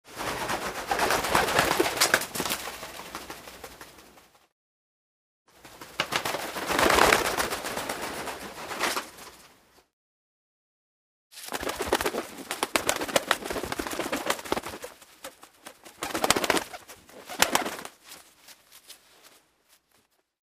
Звуки голубей